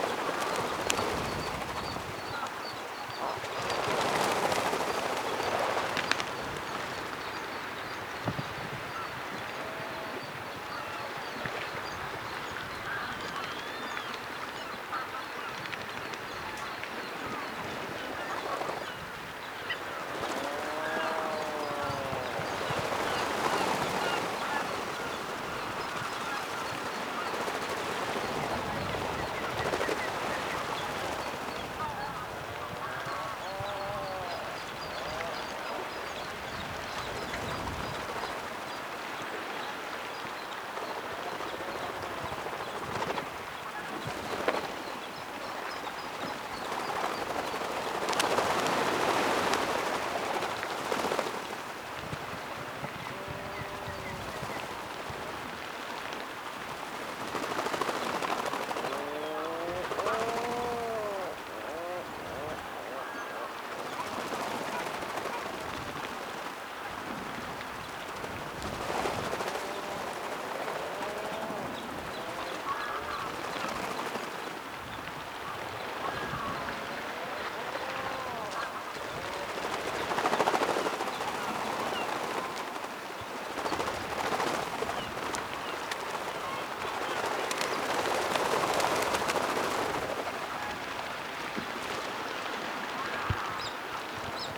Click the audio player below to hear a real puffling call, recorded from the wild: